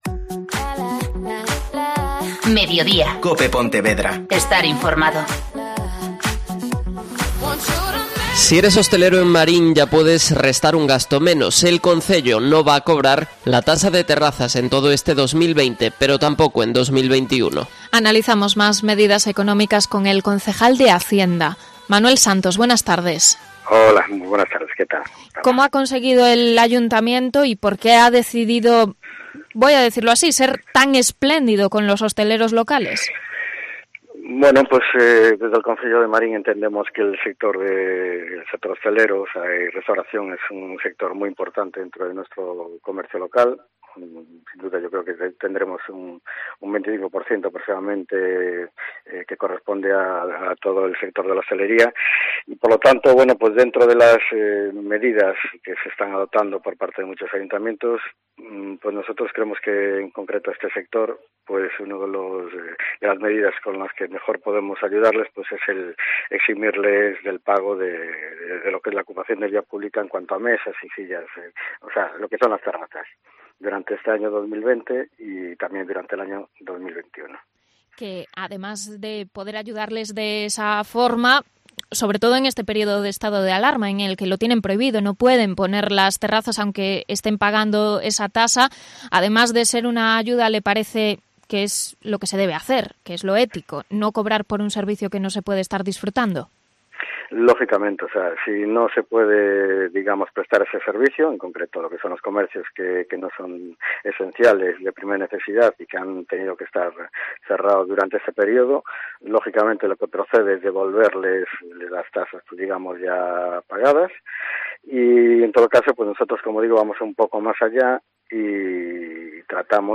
Entrevista al concejal de Hacienda de Marín sobre tasas